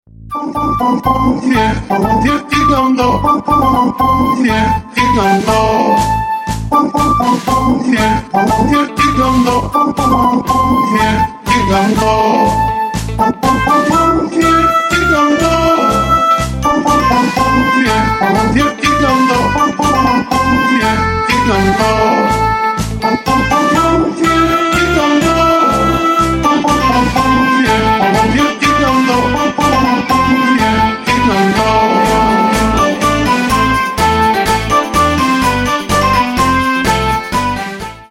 Рингтоны Ремиксы